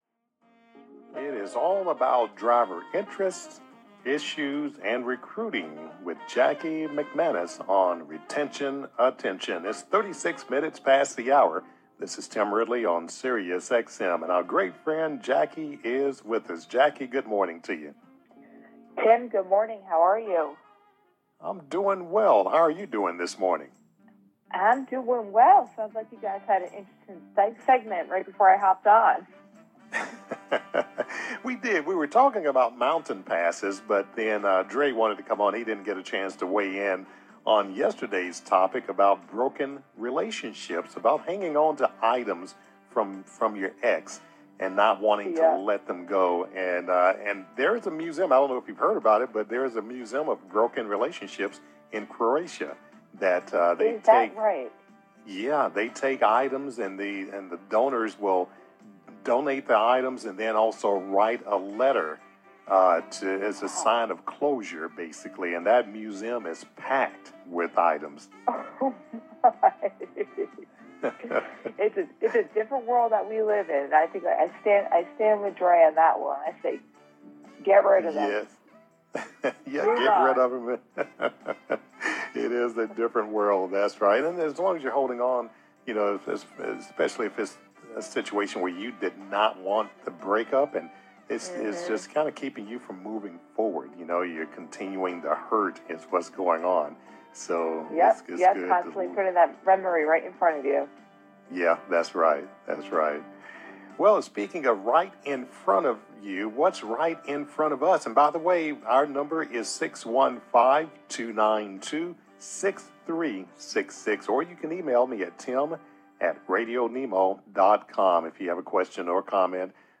One caller explains that he stays with his carrier because he feels that they truly understand what drivers need, and have figured out how to treat drivers right.